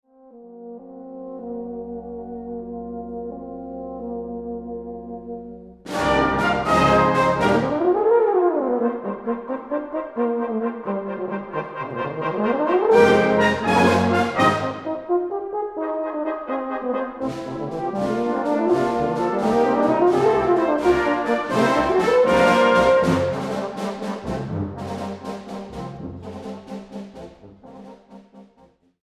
STYLE: Brass Band